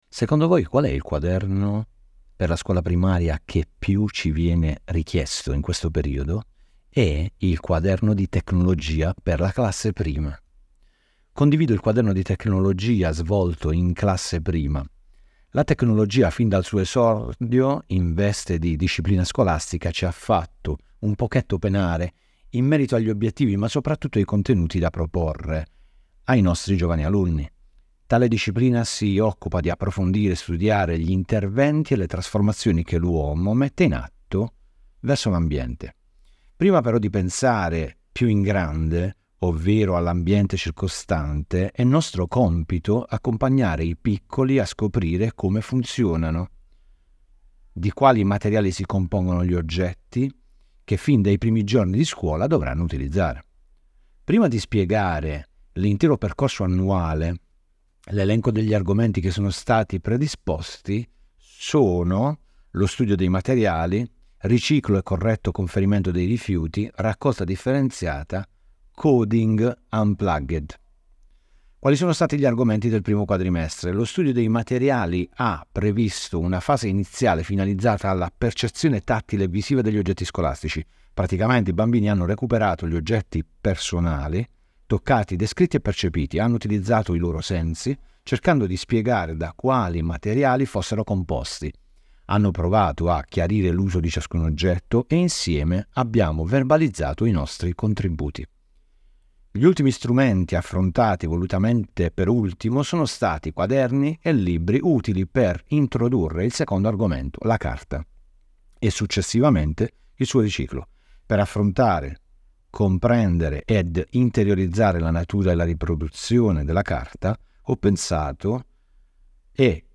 Audio descrizione dell’articolo